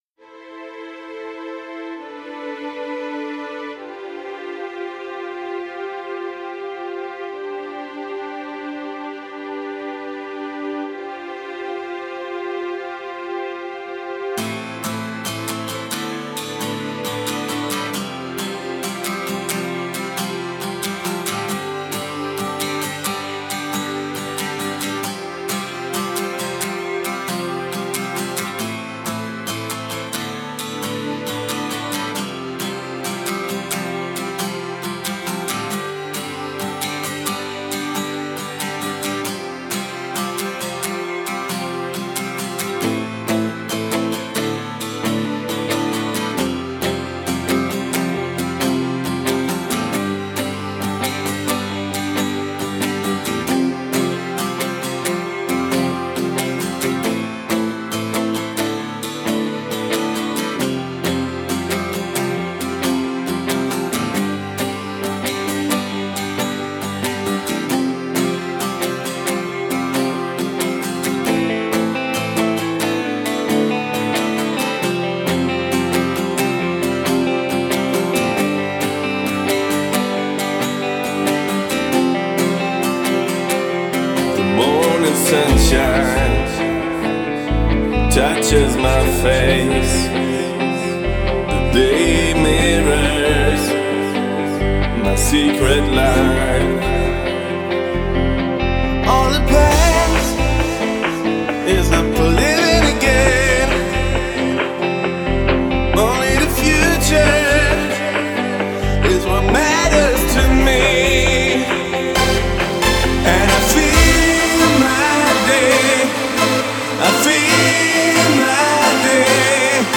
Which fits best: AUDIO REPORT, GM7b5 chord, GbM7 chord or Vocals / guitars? Vocals / guitars